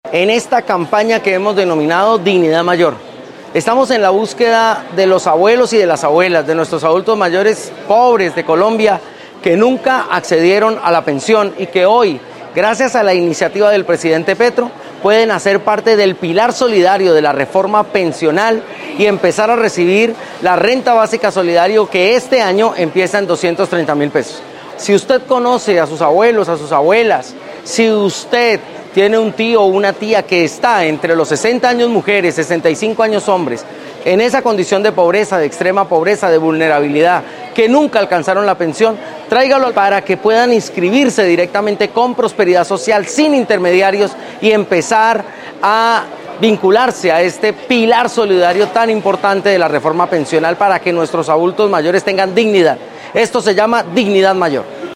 Declaraciones de Mauricio Rodríguez Amaya, director de Prosperidad Social.